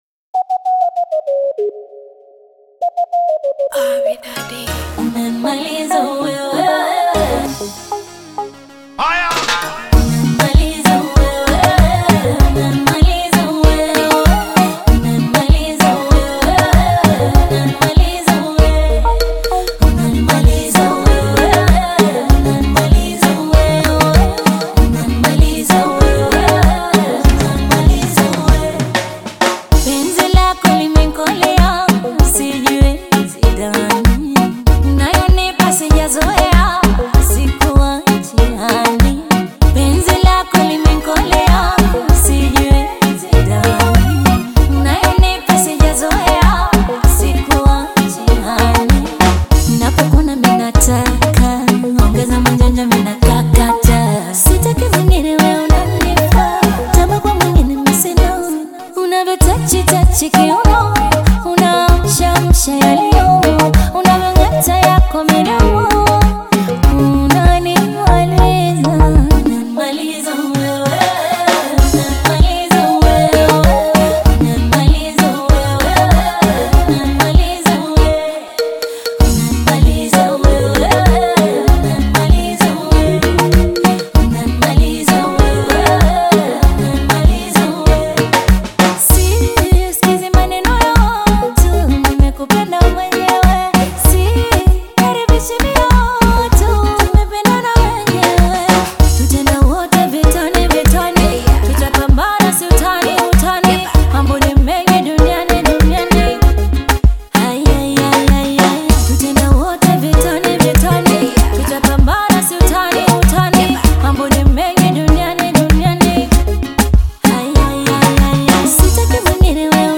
Bongo Fleva Kitambo